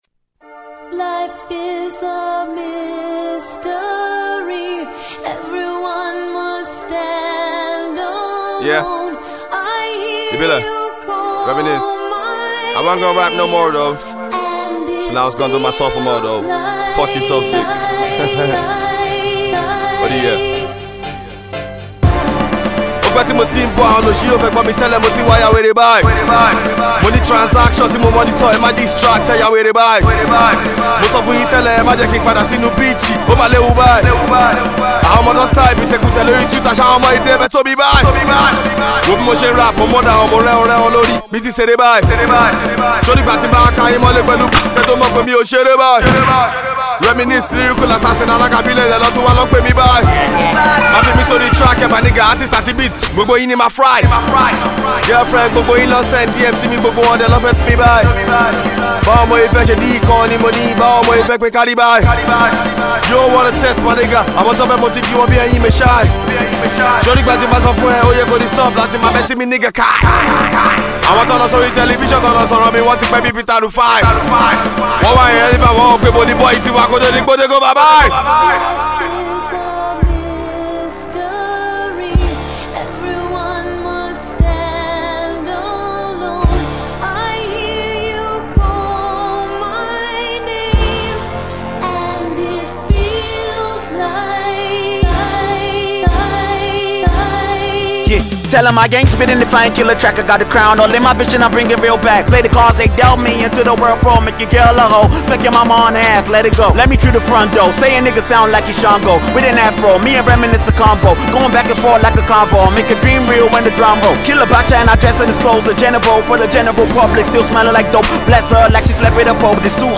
Indigenous Yoruba Rap star